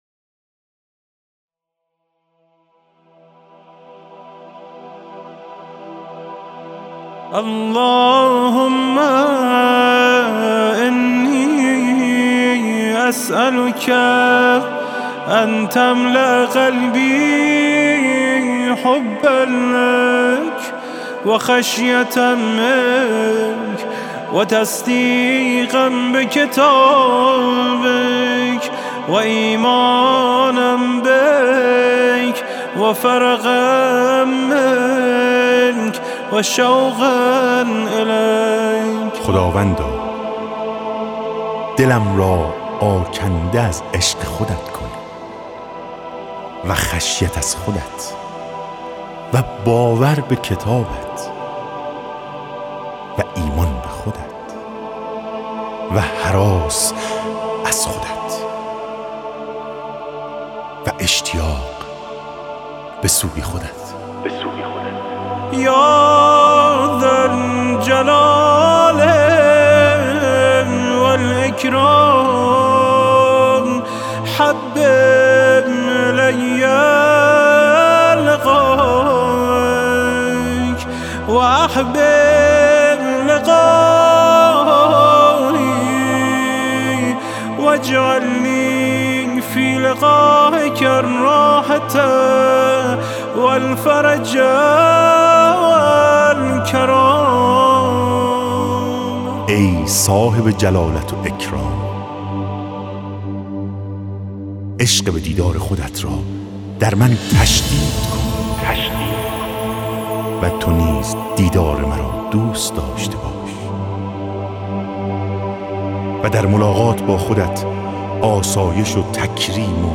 به همین مناسبت نماهنگی در فضای مجازی منتشر شده است .